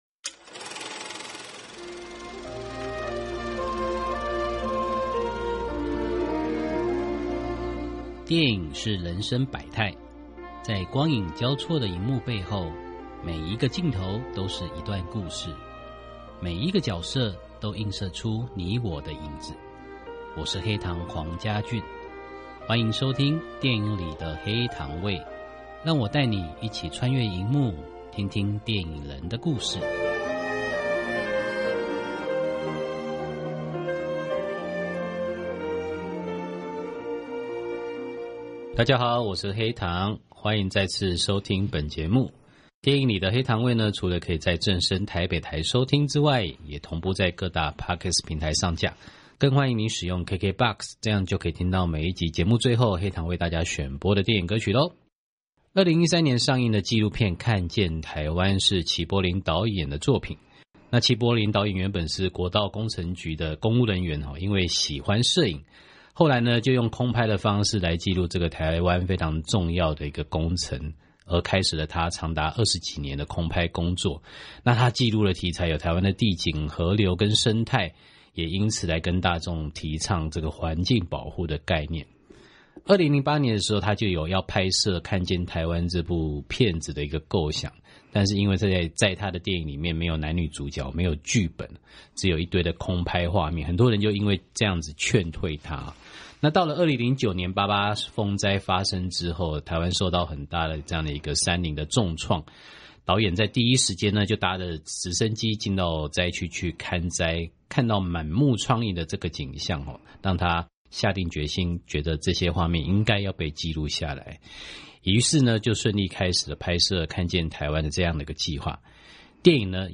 訪問大綱： 1.